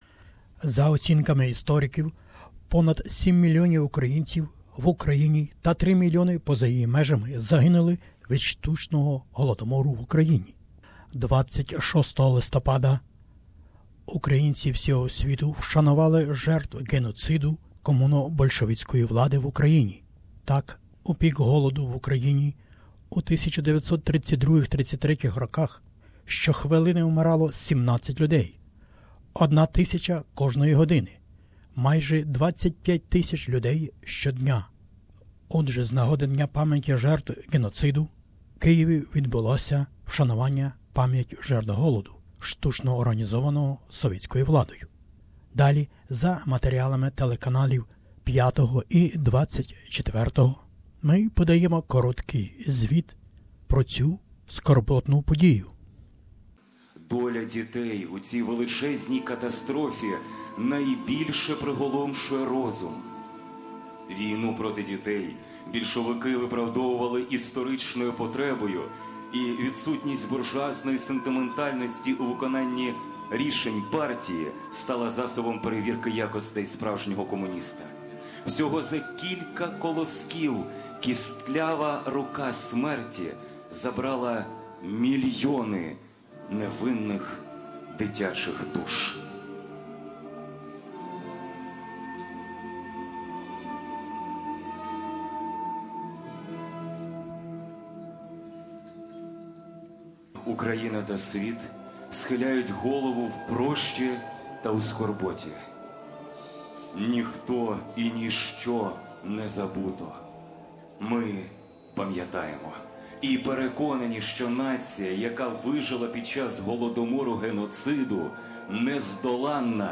Report from Kyiv, 26/11/2016.